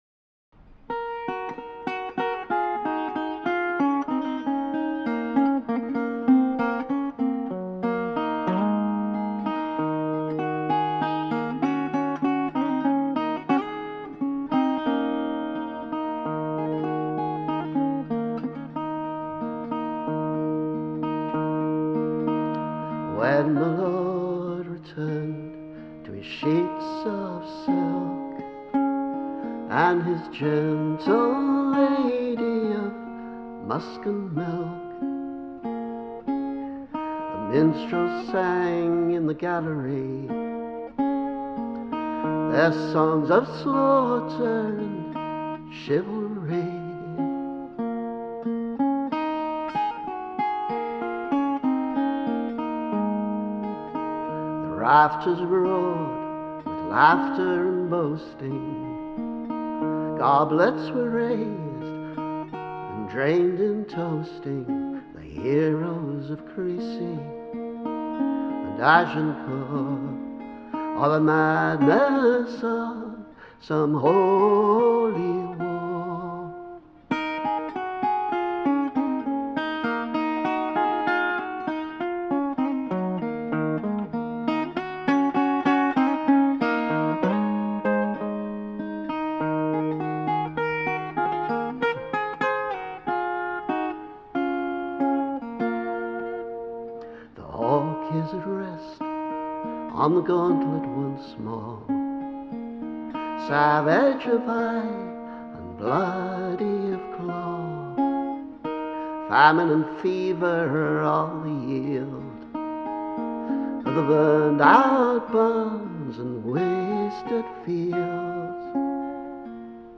Recorded during my recent ‘Live Lounge’ session
chivalry-live.mp3